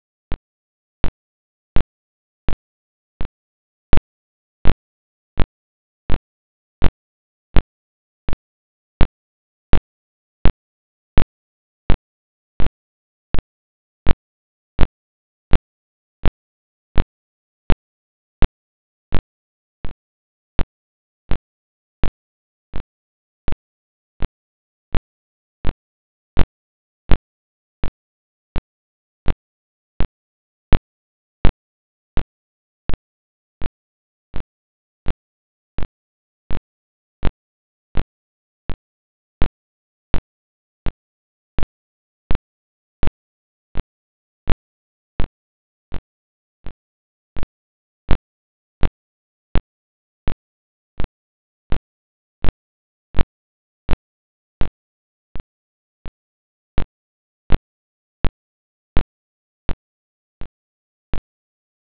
Live Continuum Live Spectrum continuum plot pulsar sound